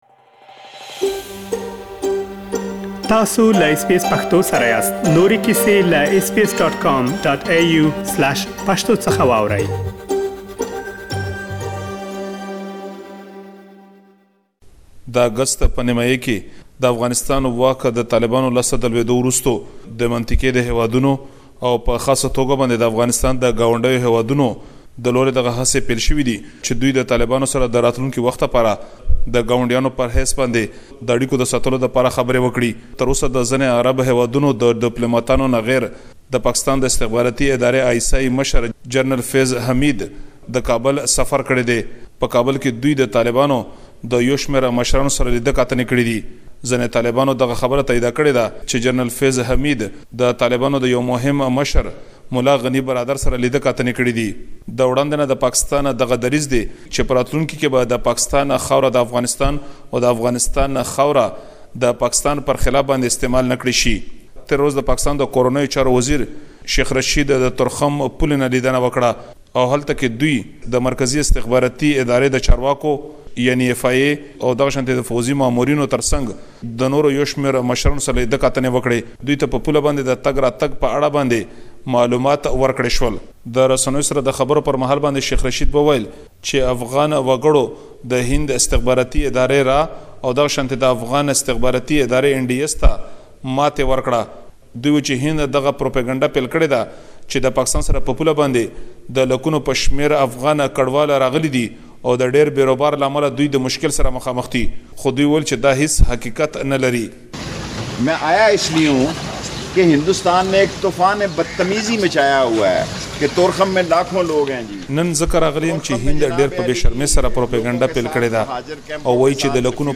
رپوټ